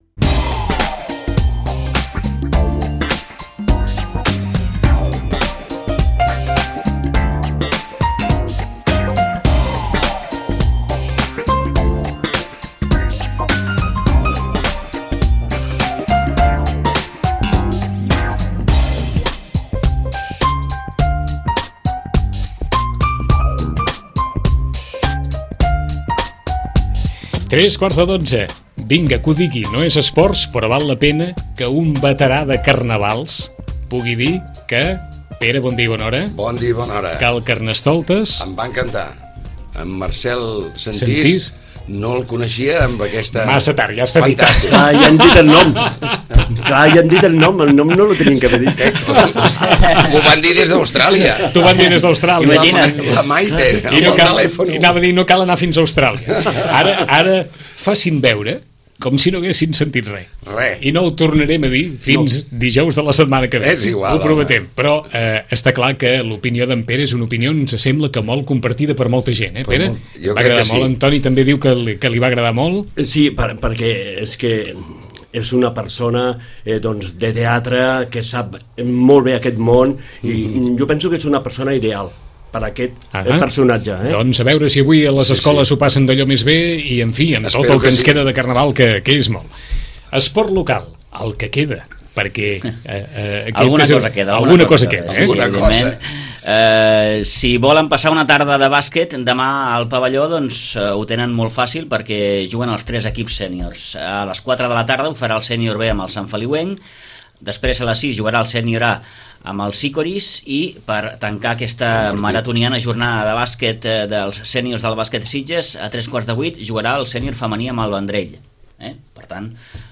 La tertúlia esportiva dels divendres